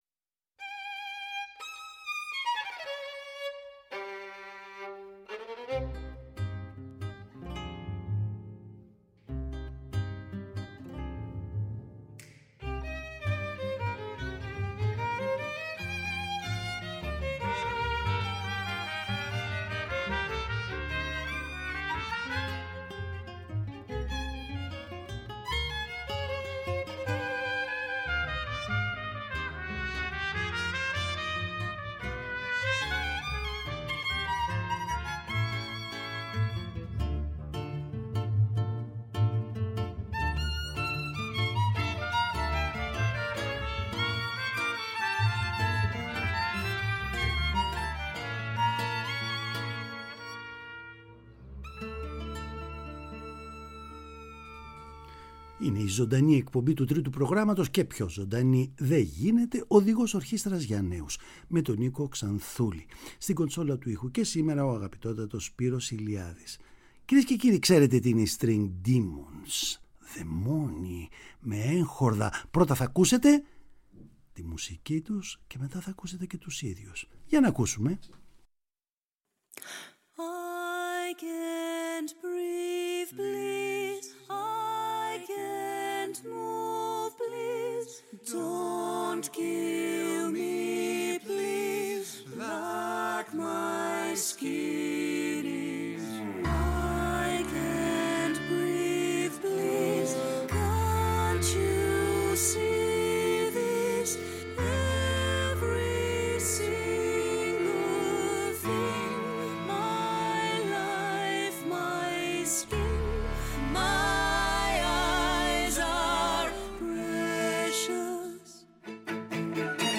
Παραγωγή-Παρουσίαση: Νίκος Ξανθούλης